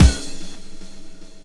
29DR.BREAK.wav